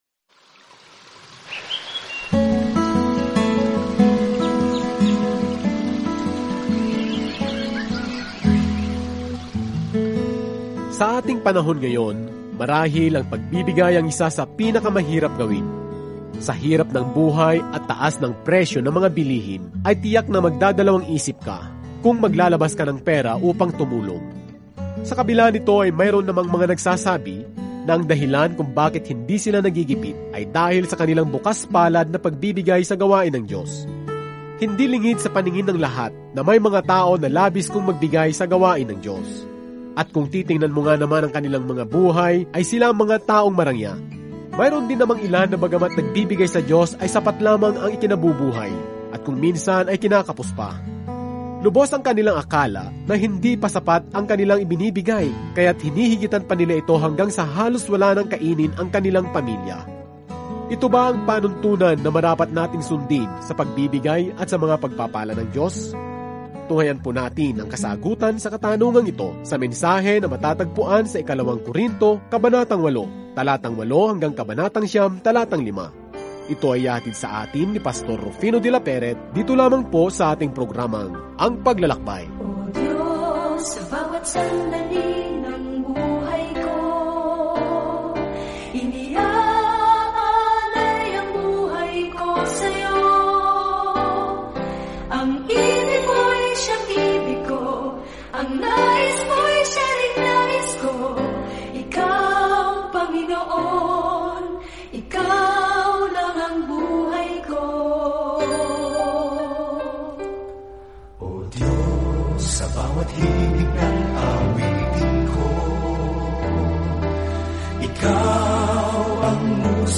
Araw-araw na paglalakbay sa 2 Corinthians habang nakikinig ka sa audio study at nagbabasa ng mga piling talata mula sa salita ng Diyos.